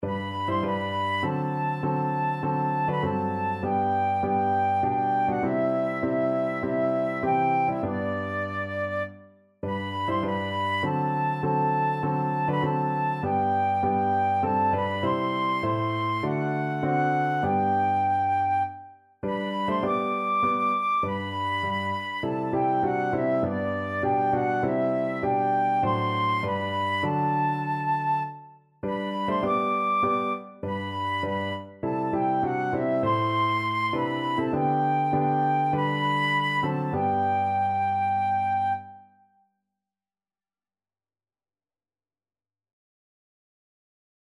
4/4 (View more 4/4 Music)
Classical (View more Classical Flute Music)